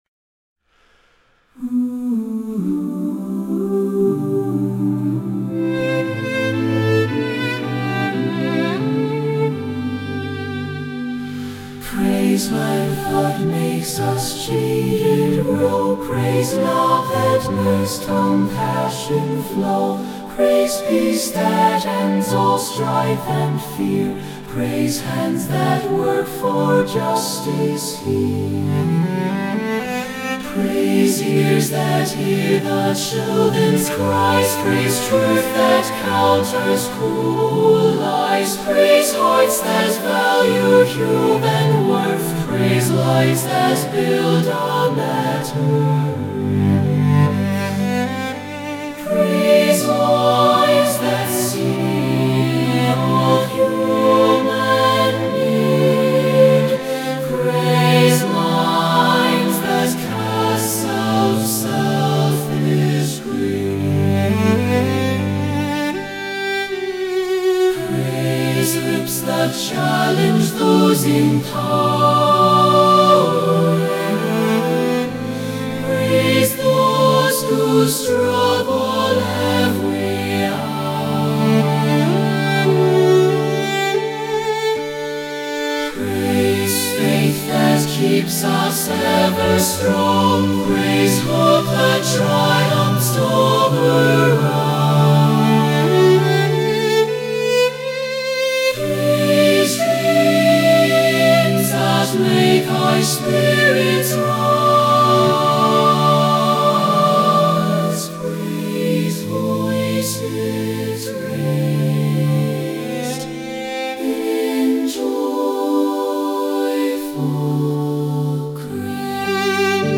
Since I have limited resources I used AI (artificial intelligence ). I instructed the Suno software to create a small choir (SATB) and accompany the voices with piano and string trio (violin, viola, and cello).  They pretty much stuck to the melodies except for the third verse where they go a little wild.